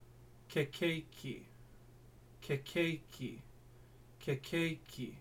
Hawaiian pronunciation of “ke keiki”